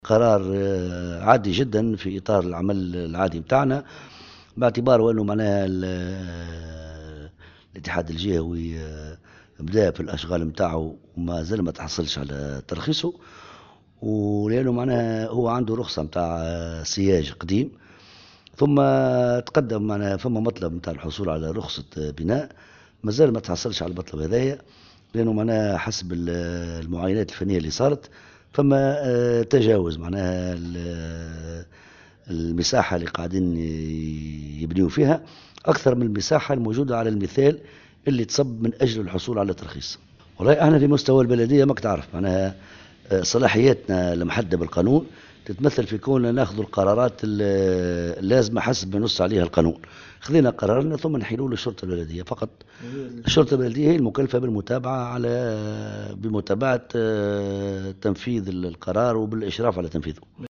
وأضاف مرزوق في تصريح اليوم لمراسل "الجوهرة أف أم" أن الاتحاد انطلق في الأشغال دون الحصول على رخصة بناء، كما أن المعاينات الفنية أثبتت أن المساحة التي تمت عليها الاشغال بمقتضى رخصة سابقة أكبر من المساحة المنصوص عليها بالأمثلة الهندسية، وفق قوله.